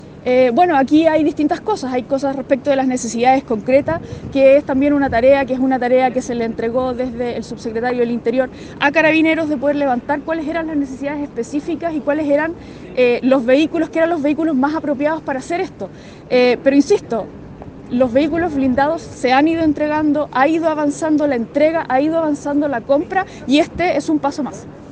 La delegada Presidencial, Daniela Dresdner, al ser consultada por la demora en la tramitación de estos recursos, dijo que se le ha pedido a Carabineros establecer sus necesidades para con la zona.